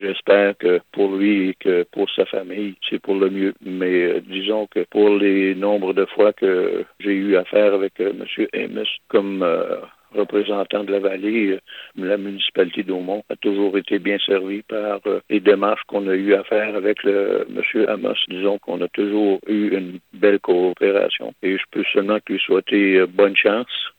Le maire de la municipalité d’Aumond, Alphée Moreau, estime d’ailleurs que la Vallée-de-la-Gatineau perdra, aux prochaines élections fédérales, un allié pour les municipalités :